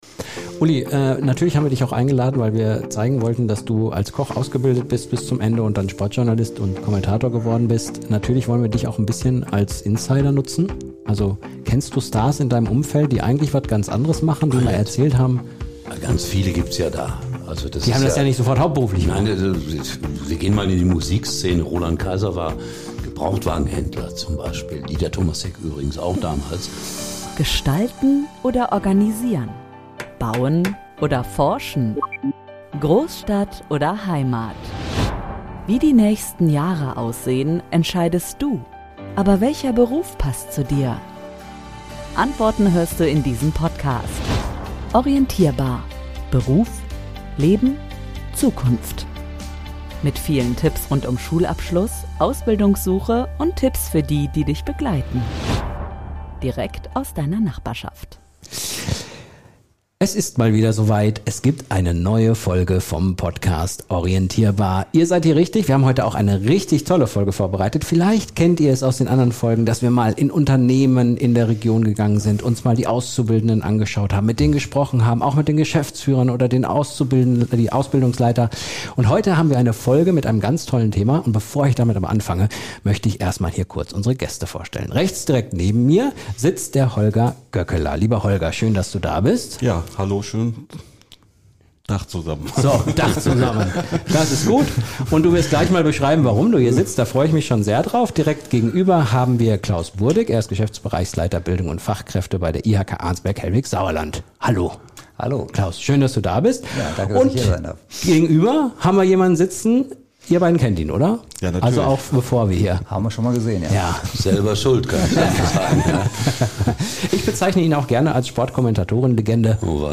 Ein inspirierendes Gespräch über Chancen, Mut und den Wert von Erfahrung.